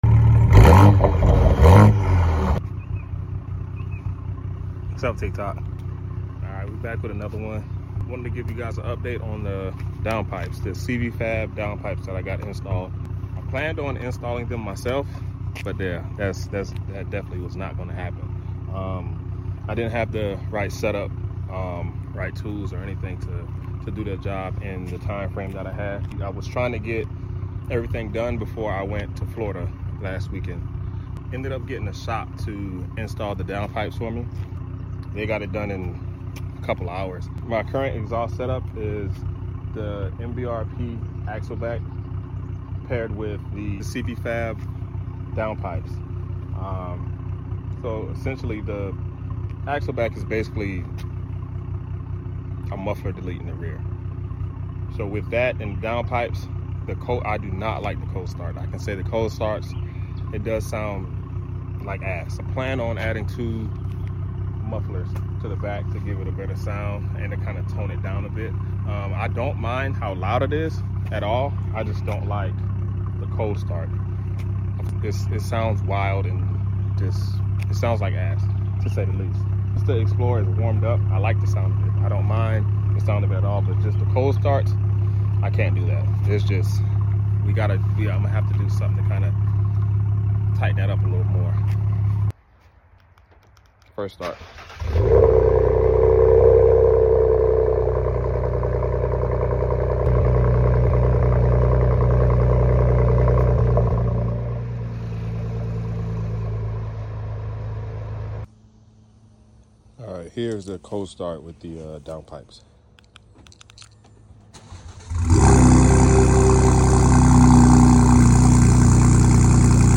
MBRP Axleback Paired With CV Sound Effects Free Download